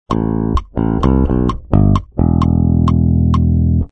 🔊Feel Good Inc Bass Tab 2👇🏻
Después de tocar la pregunta del riff necesitamos tocar la respuesta del riff y aquí está, como verás concluye perfectamente este riff increíble.
Feel-Good-Inc-riff-2.mp3